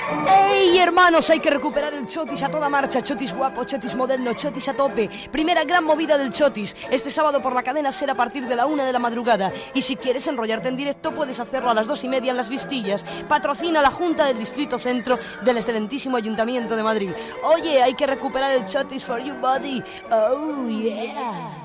Promoció del programa especial "Fiestas de la Paloma" de Madrid